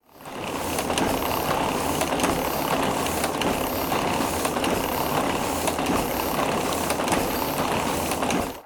Heidelberg printing press #2
Sound of a Heidelberg printing press.
UCS Category: Machines / Industrial (MACHInd)
Channels: Stereo
Conditions: Indoor
Realism: Realistic
Equipment: Zoom H4e